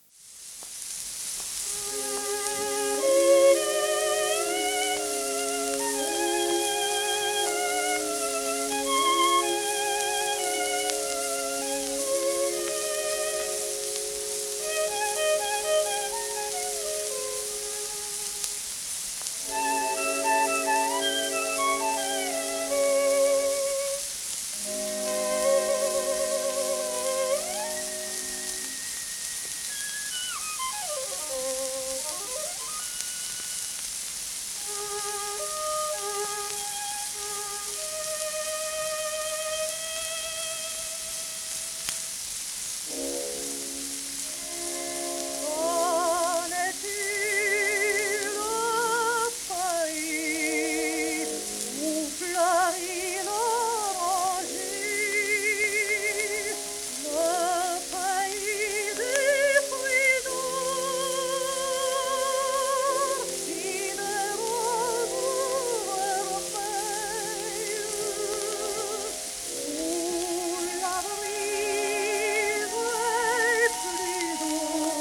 オーケストラ
12インチ片面盤
1915年録音